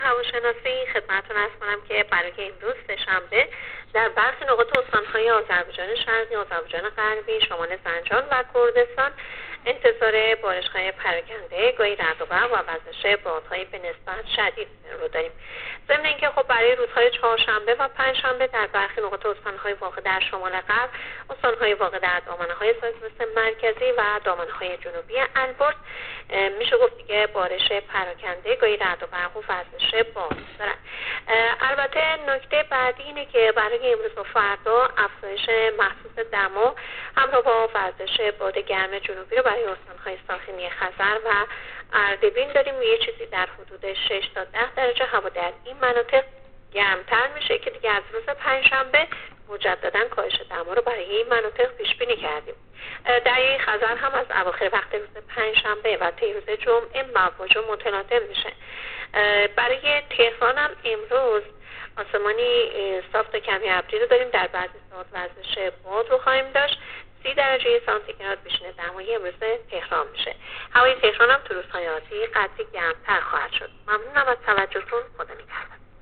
کارشناس سازمان هواشناسی کشور در گفت‌وگو با رادیو اینترنتی وزارت راه و شهرسازی، آخرین وضعیت آب‌و‌هوای کشور را تشریح کرد.
گزارش رادیو اینترنتی از آخرین وضعیت آب‌‌و‌‌‌هوای ۳۱ فروردین